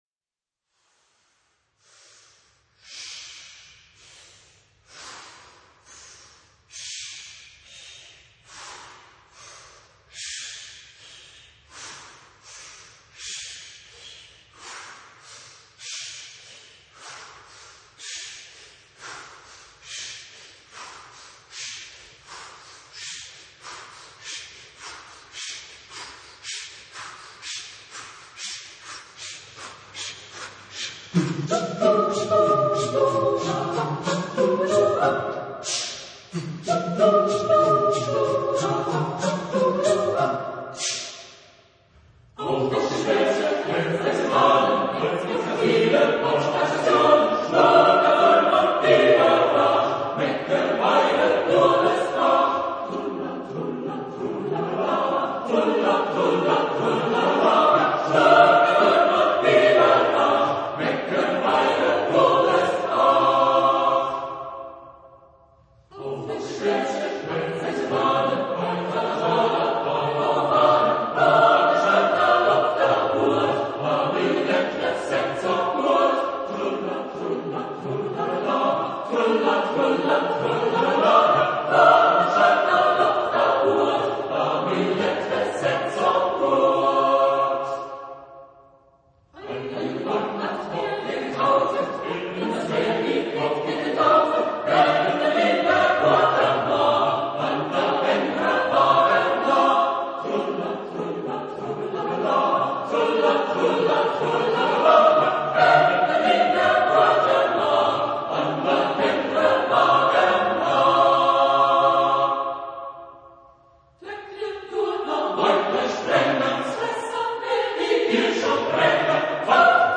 Genre-Style-Forme : Folklore ; Chanson ; Rap ; Profane
Type de choeur : SAATB  (5 voix mixtes )
Tonalité : do majeur